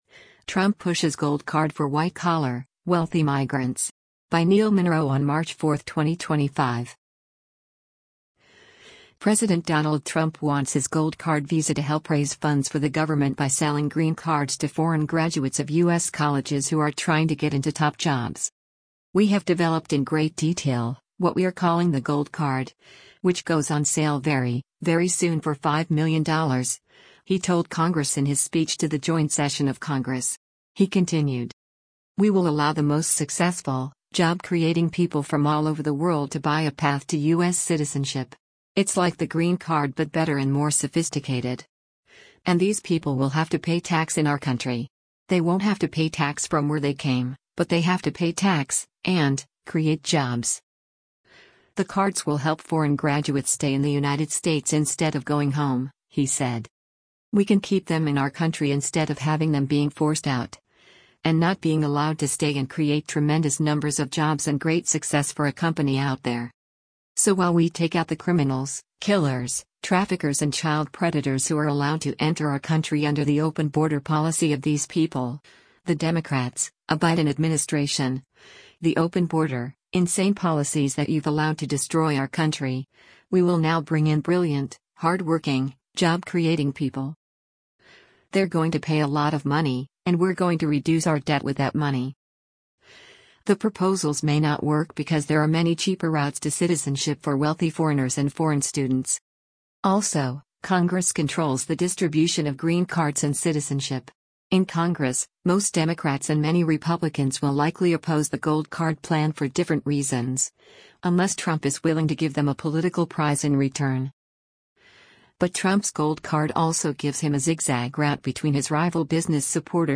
“We have developed in great detail, what we are calling the Gold Card, which goes on sale very, very soon for $5 million,” he told Congress in his speech to the Joint Session of Congress.